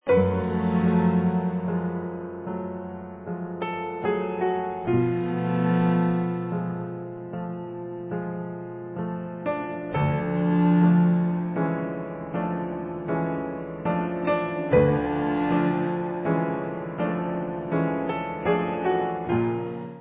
Brass punk from finland